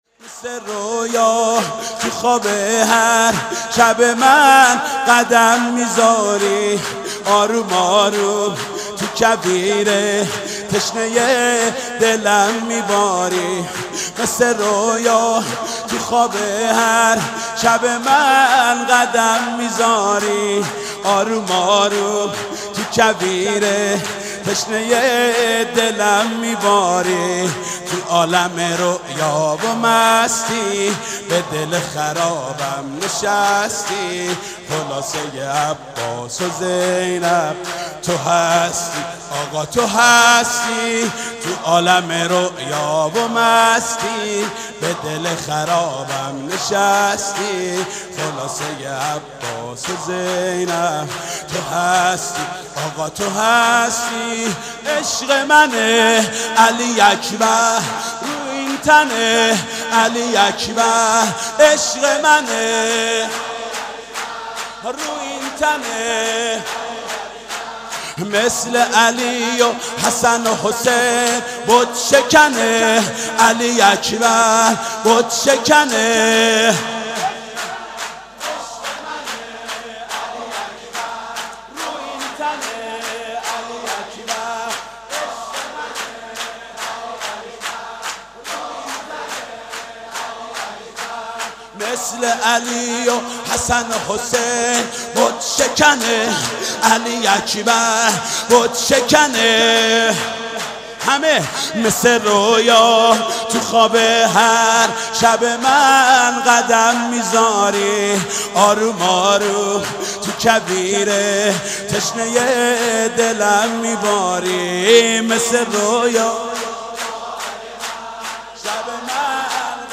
سرود: مثل رویا تو خواب هر شب من قدم میزاری